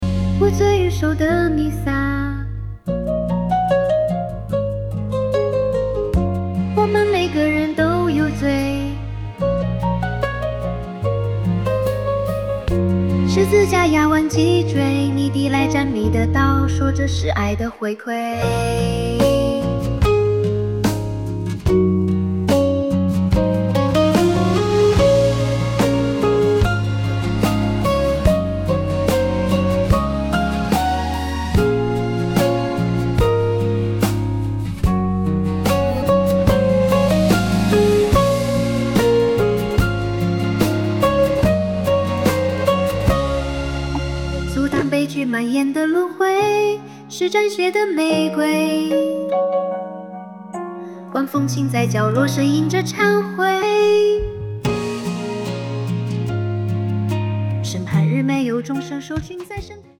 *——暗黑哥特风×古典叙事——*
人工智能生成式歌曲